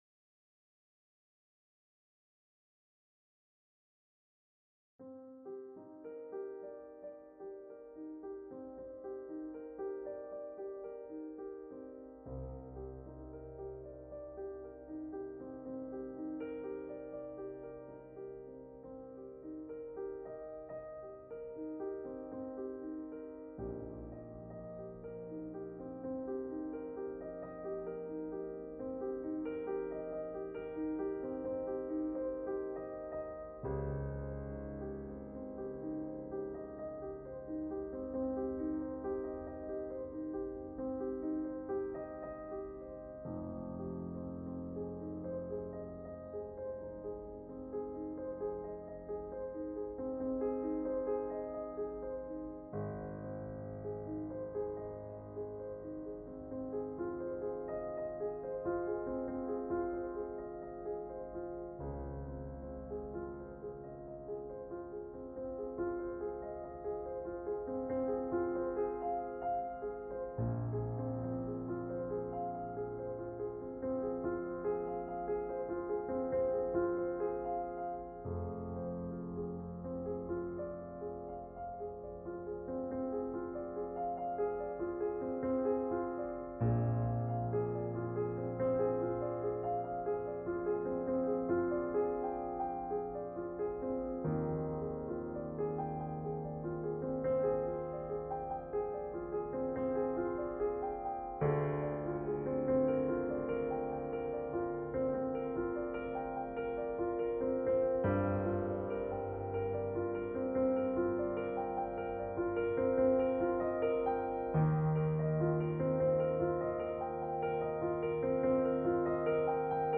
Minimalistic music for the movie - Piano Music, Solo Keyboard
Minimalistic music for the movie
Hi guys, I am posting one of the pieces for solo piano, which I wrote for the movie.